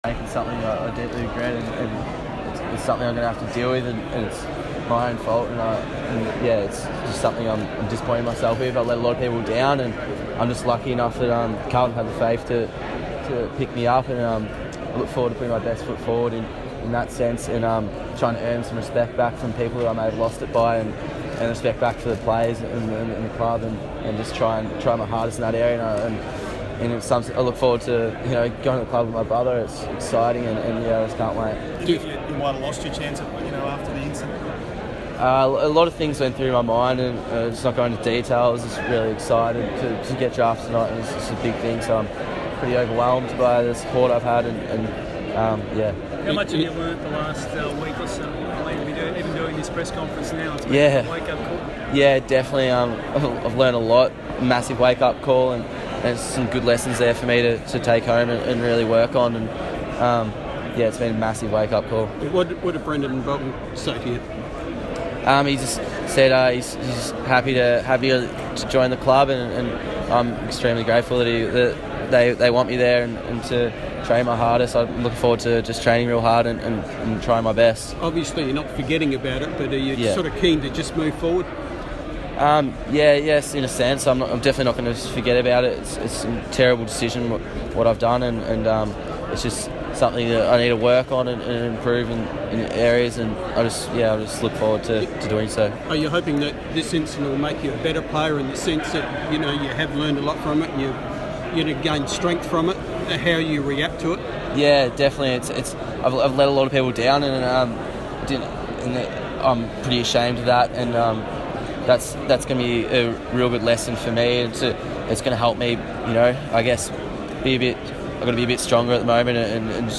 Charlie Curnow press conference - draft night
Charlie Curnow, Carlton's pick 12 in the 2015 national draft, speaks to the media for the first time as a Blue.